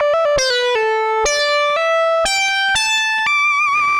Index of /musicradar/80s-heat-samples/120bpm